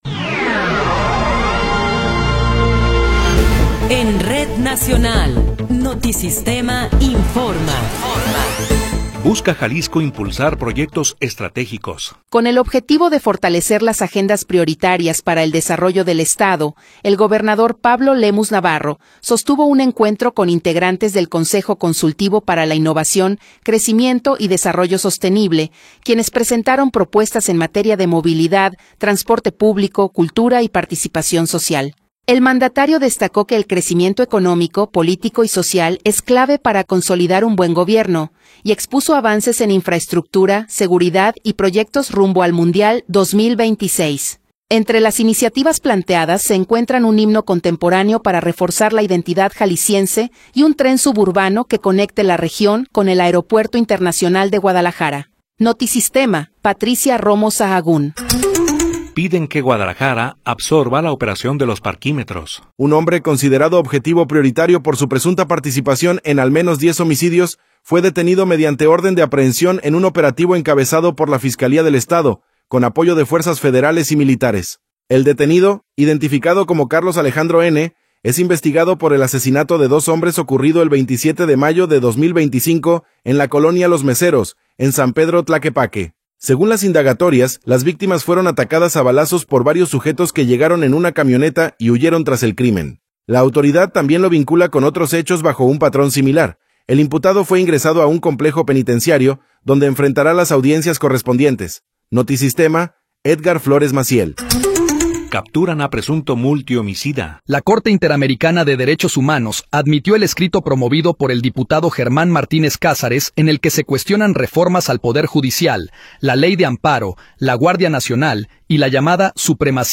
Noticiero 19 hrs. – 11 de Febrero de 2026